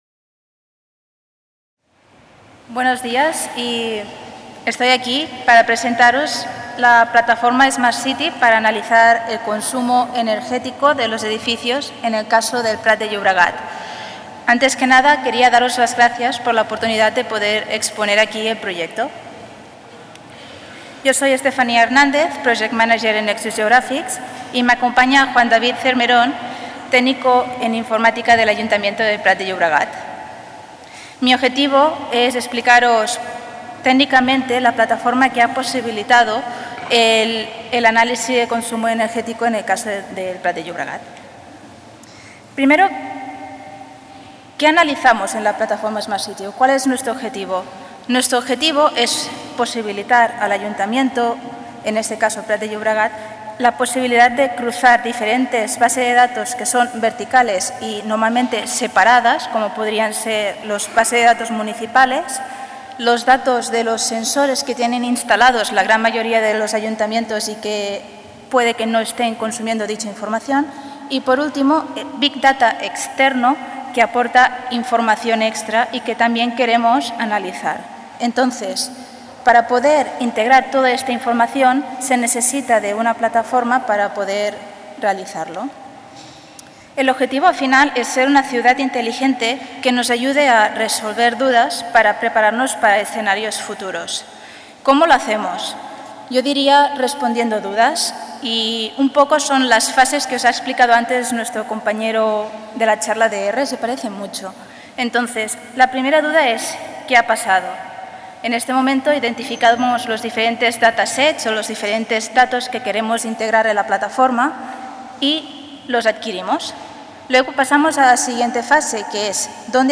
Conferència
dins les XVI Jornadas de SIG Libre. Es tracta la gestió i consum d'energia al municipi de El Prat de Llobregat i com poder recollir dades i elaborar productes d'Informació geogràfica per gestionar millor aquesta energia. S'exposa de manera pràctica el funcionament d'una plataforma smart city de codi obert per avaluar l'eficiència energètica dels edificis d'aquest municipi  Aquest document està subjecte a una llicència Creative Commons: Reconeixement – No comercial – Compartir igual (by-nc-sa) Mostra el registre complet de l'element